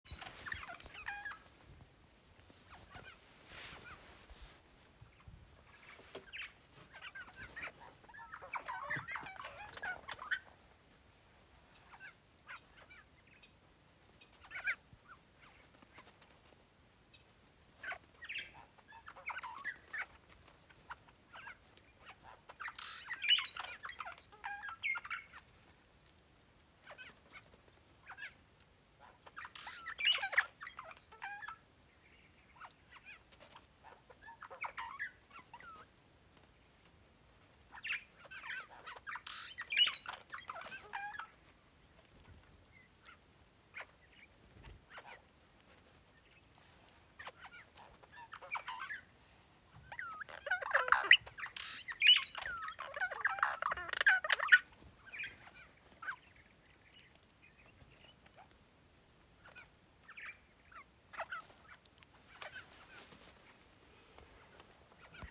oiseaux.m4a